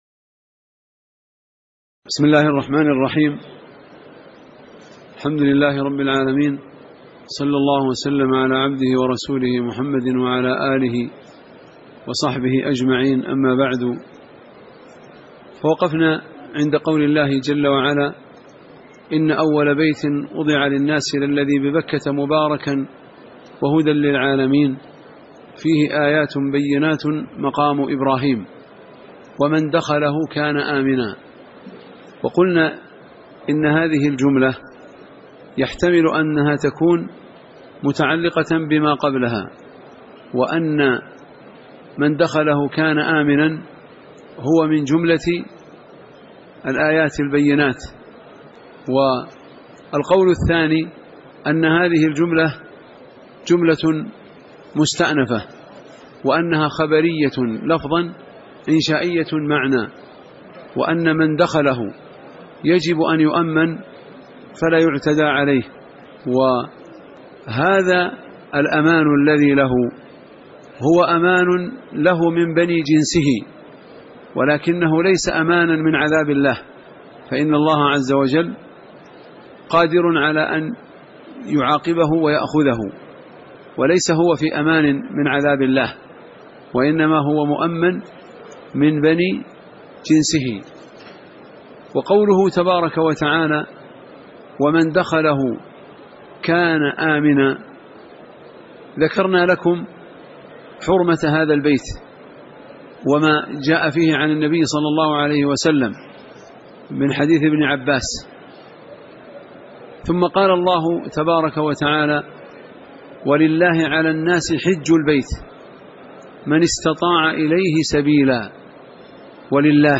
تاريخ النشر ٢٢ ذو القعدة ١٤٣٨ هـ المكان: المسجد النبوي الشيخ